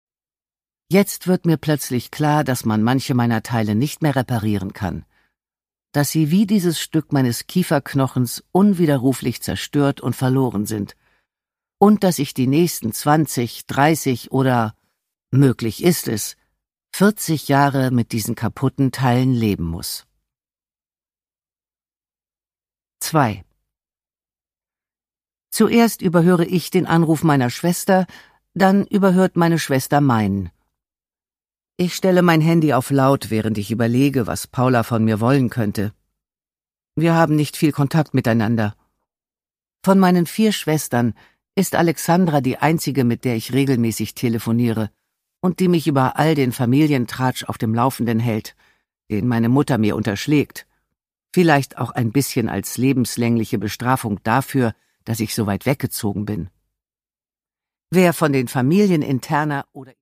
Produkttyp: Hörbuch-Download
Gelesen von: Nina Petri